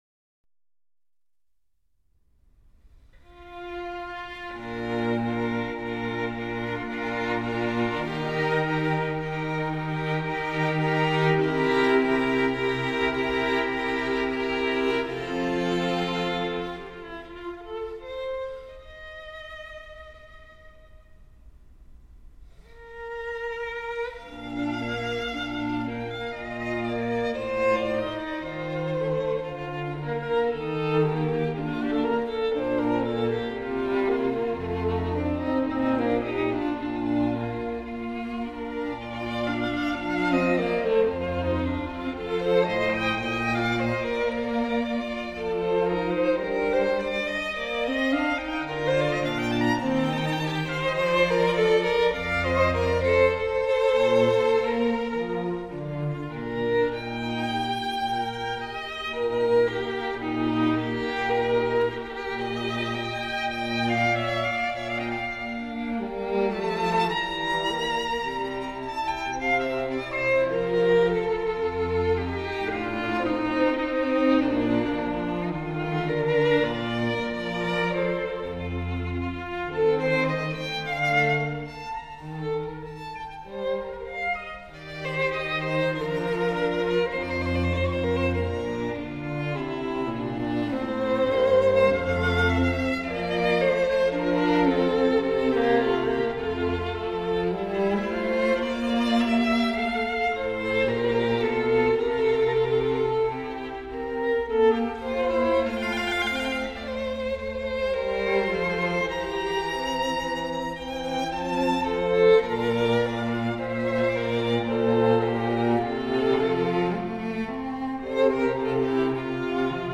A group of three musicians.
Sonate (Trio) in B flat
Adagio